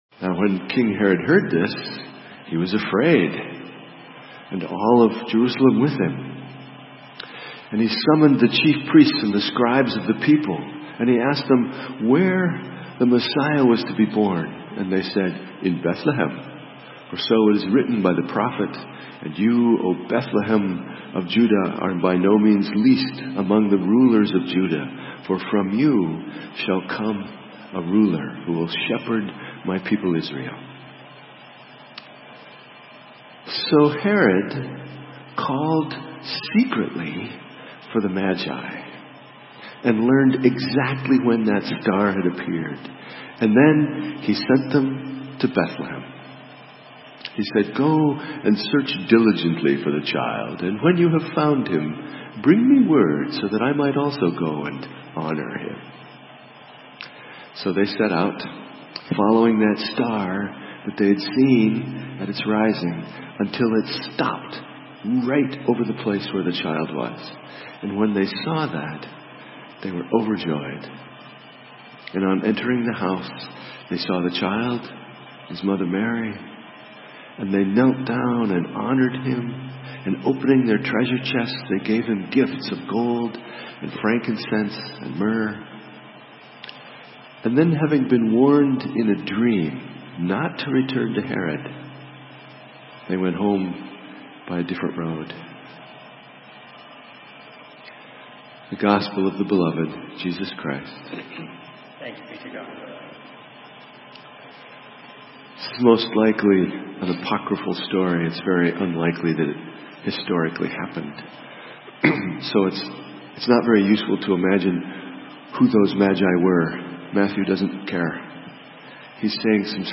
January 6, 2019 —Epiphany Sunday
SERMON    Keep searching, magi